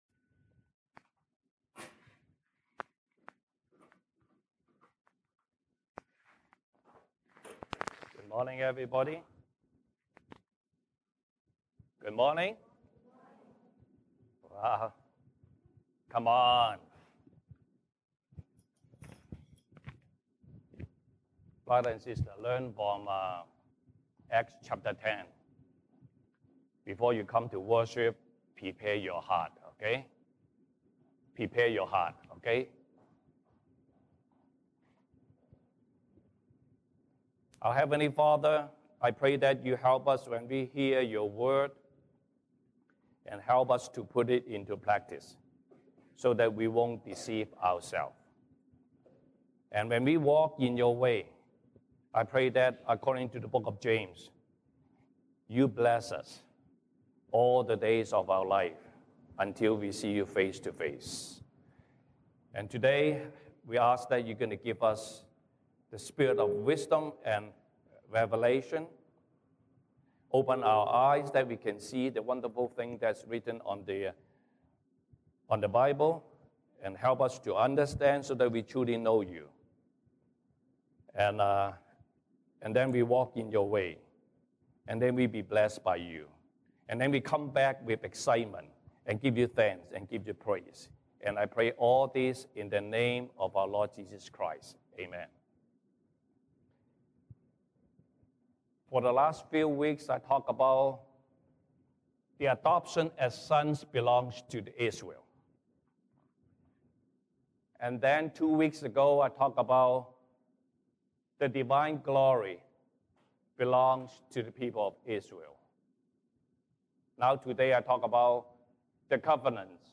西堂證道 (英語) Sunday Service English: Treasure hidden among them
Passage: 羅馬書 Romans 9:1-7 Service Type: 西堂證道 (英語) Sunday Service English